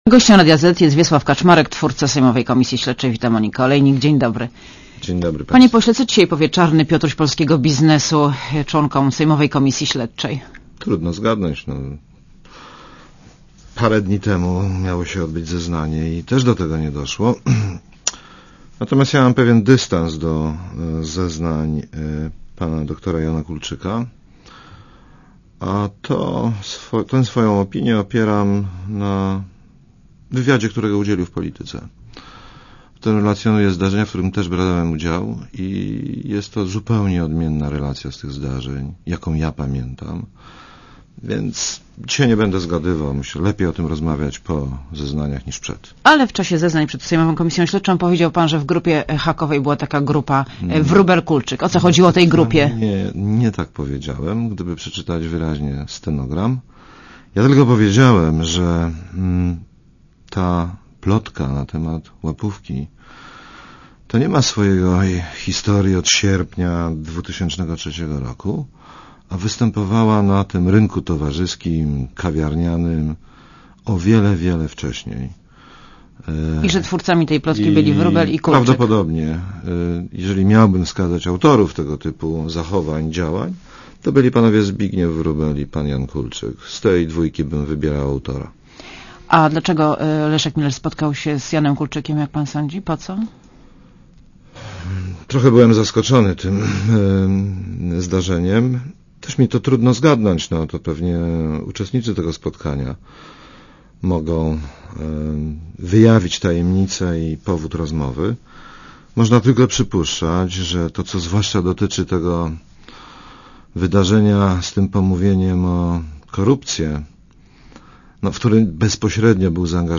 Gościem Radia Zet jest Wiesław Kaczmarek, twórca sejmowej komisji śledczej.
* Posłuchaj wywiadu * Gościem Radia Zet jest Wiesław Kaczmarek, twórca sejmowej komisji śledczej.